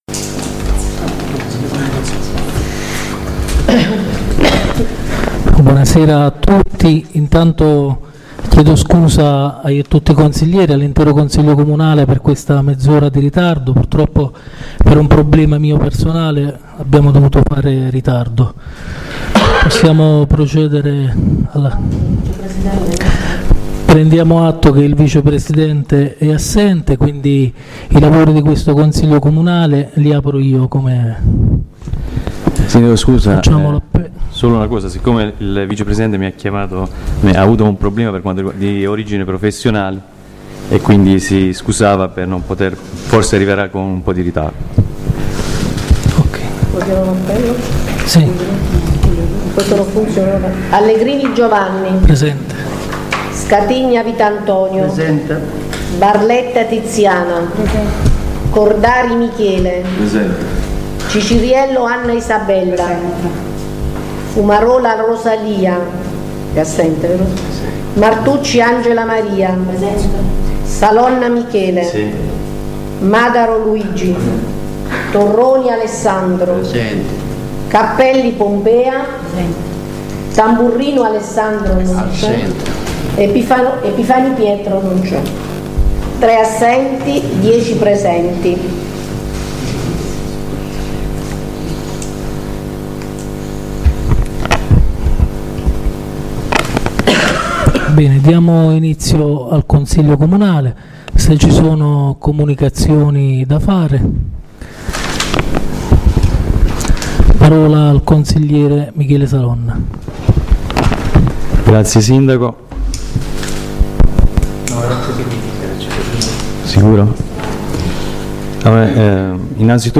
La registrazione audio del Consiglio Comunale di San Michele Salentino del 10/05/2019